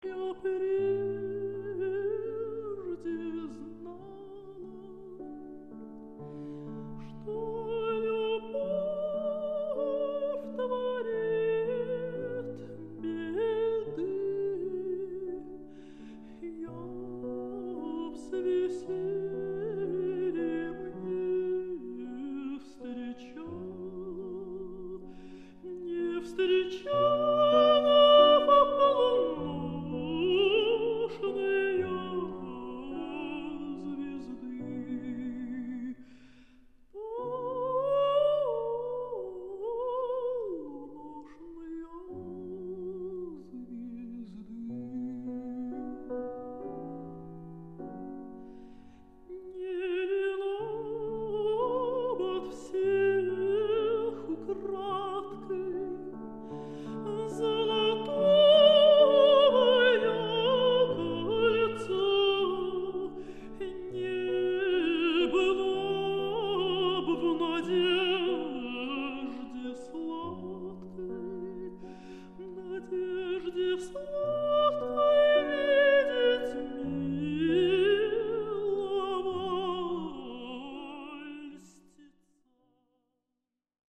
來點北國俄羅斯浪漫風情音樂吧!
十二月份，我們將為您推出濃濃俄羅斯風的浪漫曲調。